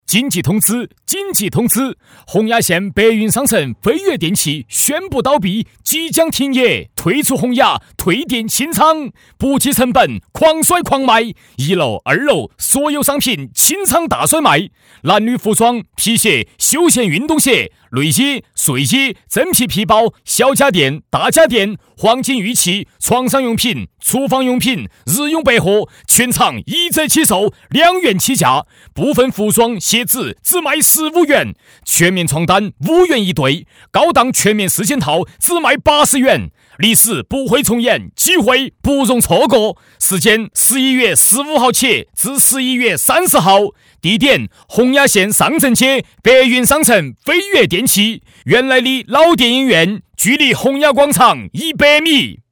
促销广告配音
男国286_广告_促销_电器促销.mp3